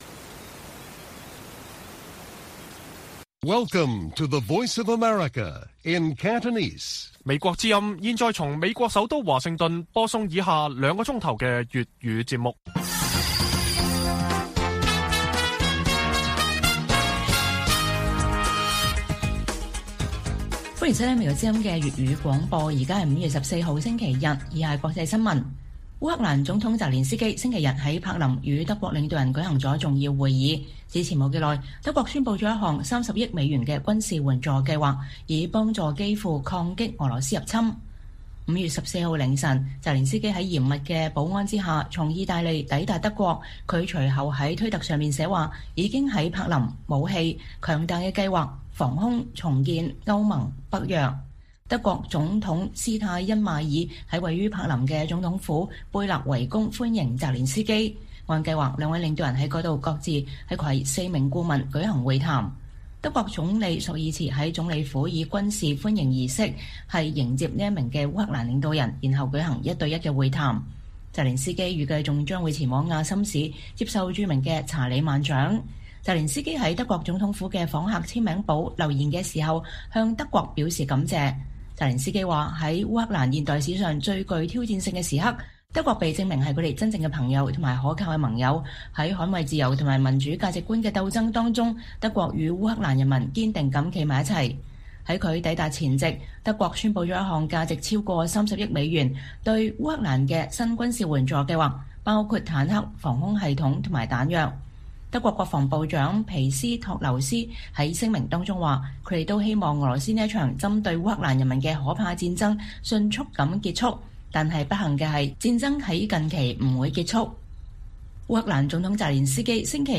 粵語新聞 晚上9-10點: 澤連斯基訪問柏林 德國宣布重要對烏軍援